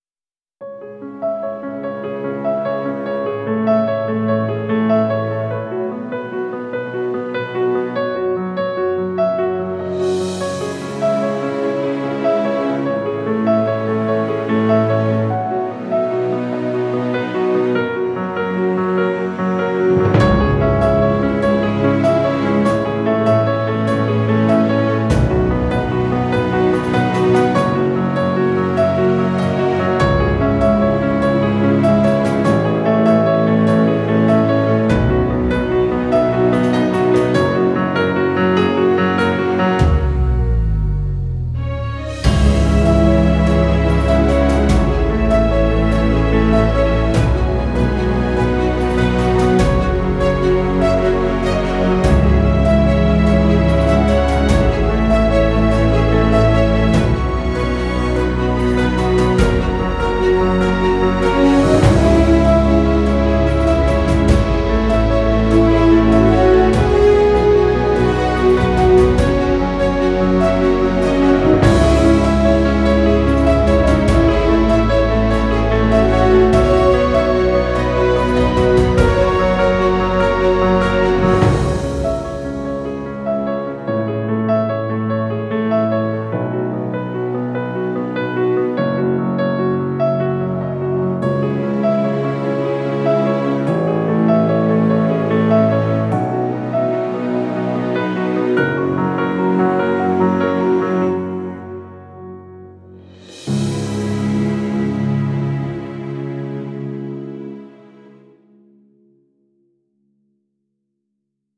Genre: filmscore, classical.